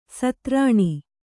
♪ satrāṇi